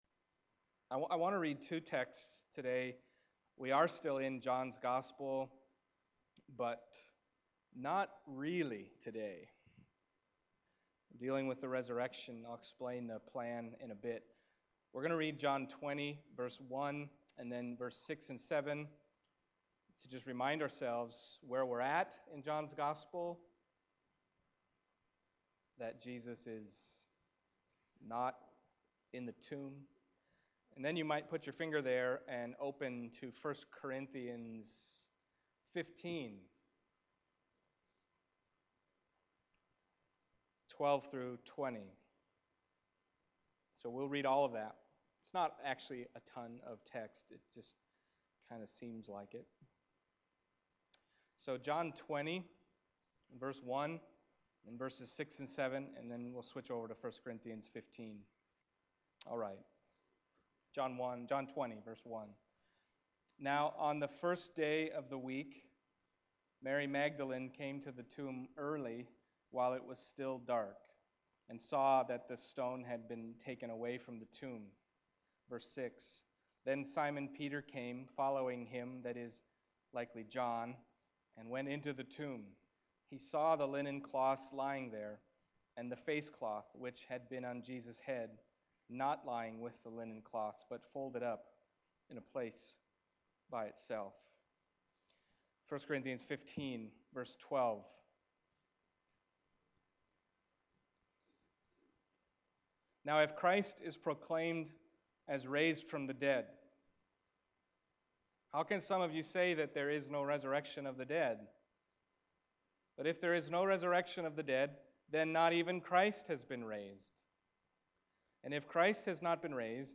1 Corinthians 15:12-20 Service Type: Sunday Morning John 20:1